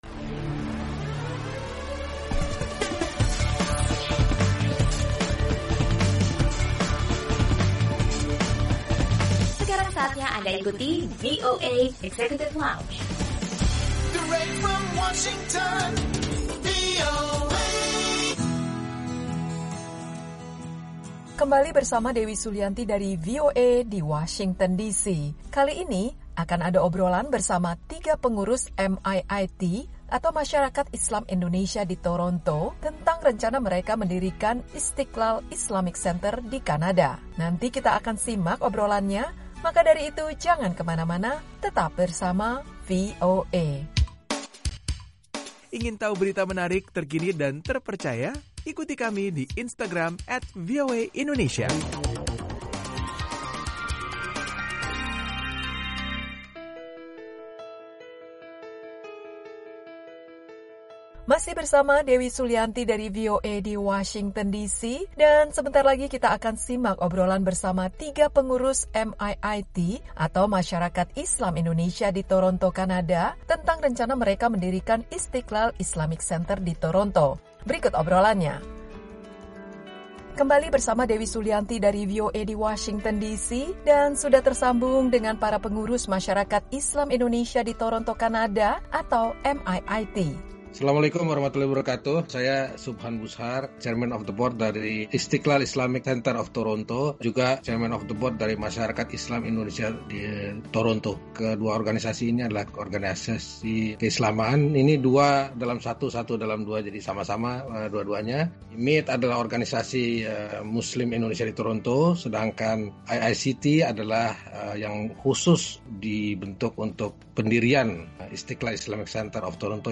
Obrolan bersama pengurus Masyarakat Islam Indonesia di Toronto tentang rencana mereka mendirikan Istiqlal Islamic Center.